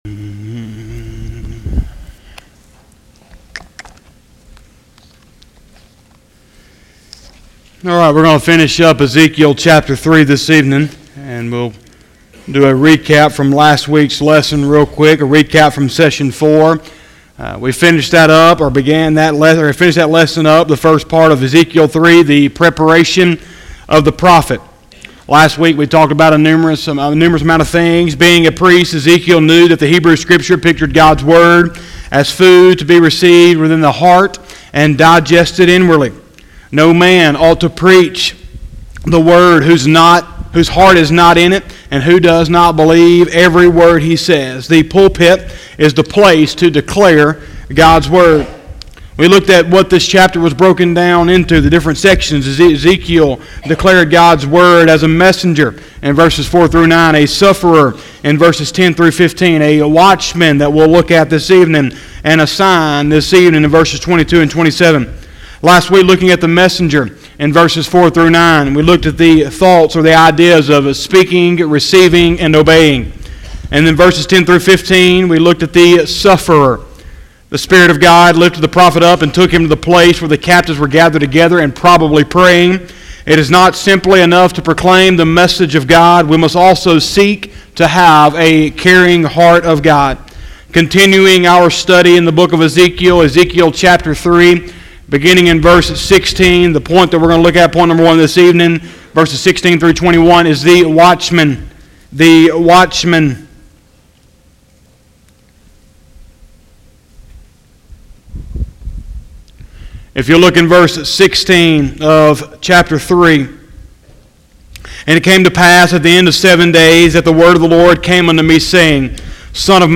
03/01/2020 – Sunday Evening Service